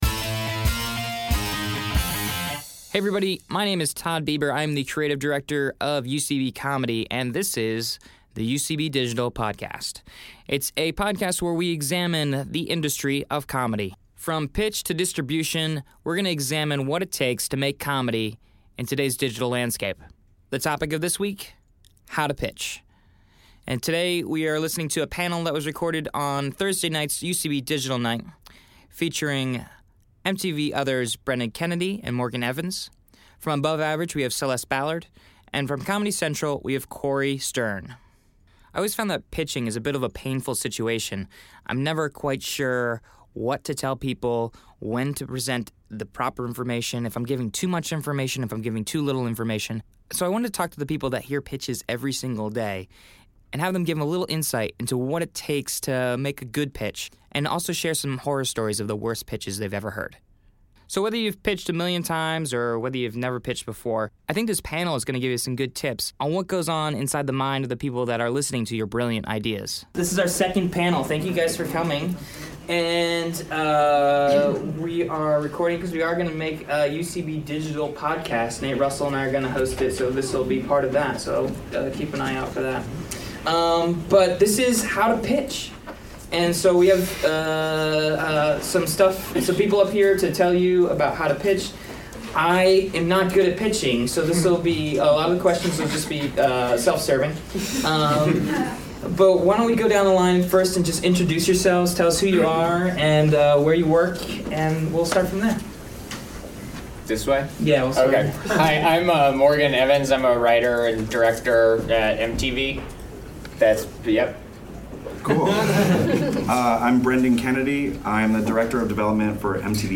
Recorded live at UCB Comedy studios in NYC.